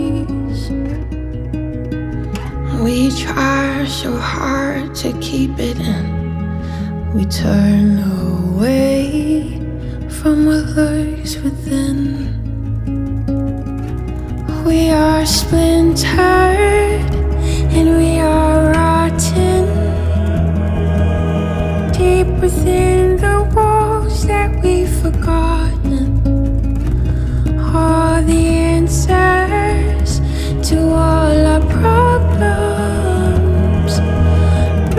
Жанр: Рок / Альтернатива / Фолк-рок